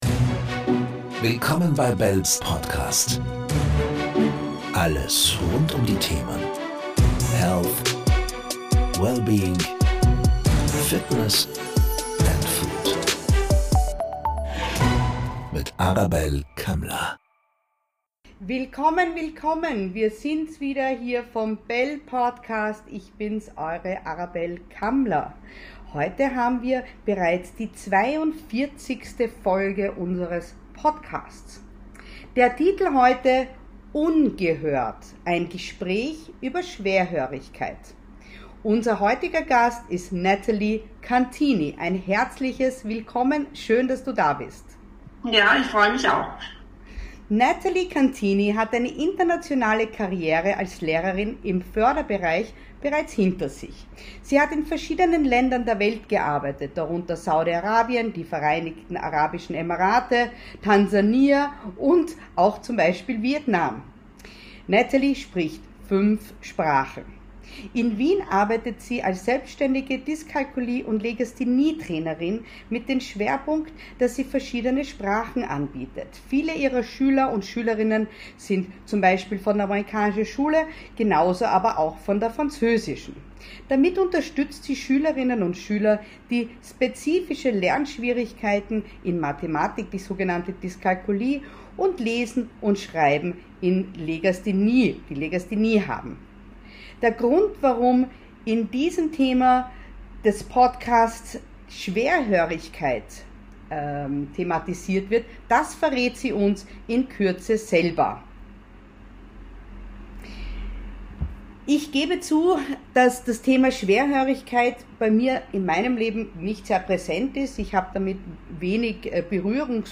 #042 Ungehört - Ein Gespräch über Schwerhörigkeit ~ BELLE Podcast